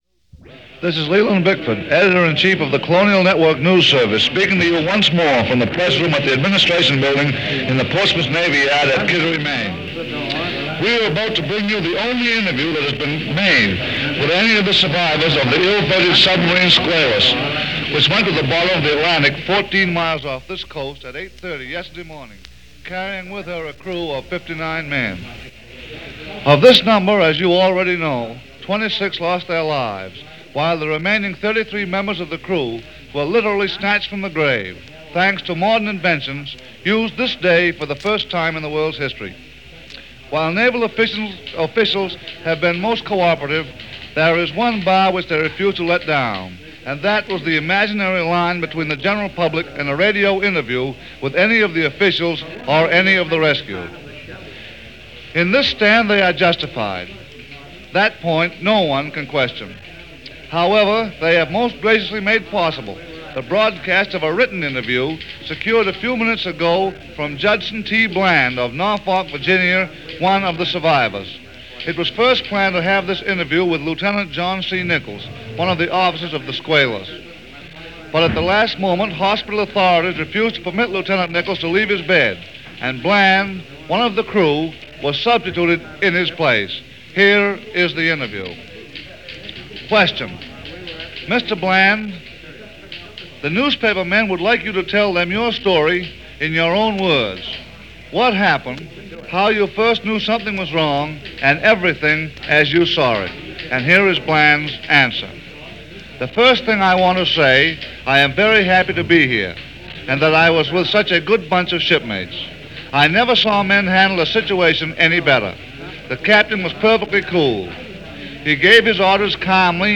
Statements to the Press
Here is that report as it was given and broadcast live on May 25, 1939.